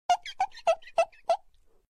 clean table.mp3